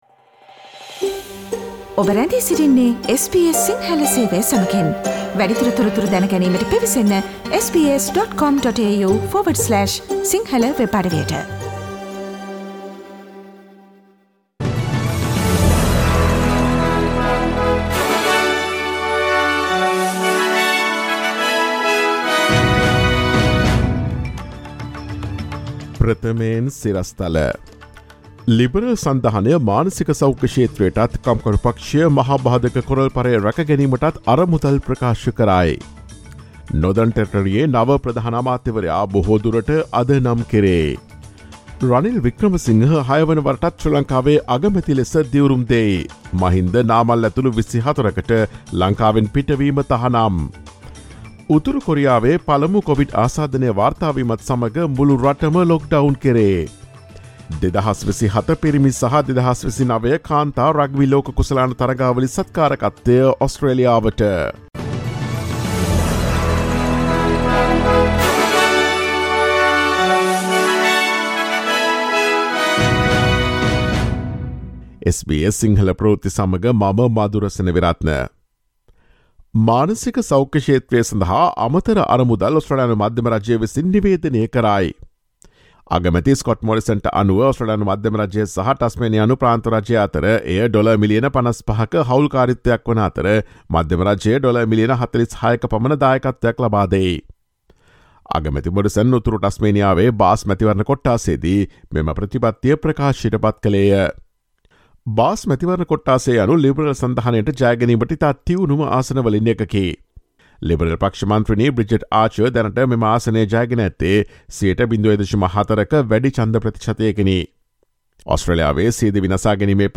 ඔස්ට්‍රේලියාවේ සහ ශ්‍රී ලංකාවේ නවතම පුවත් මෙන්ම විදෙස් පුවත් සහ ක්‍රීඩා පුවත් රැගත් SBS සිංහල සේවයේ 2022 මැයි 13 වන දා සිකුරාදා වැඩසටහනේ ප්‍රවෘත්ති ප්‍රකාශයට සවන් දීමට ඉහත ඡායාරූපය මත ඇති speaker සලකුණ මත click කරන්න.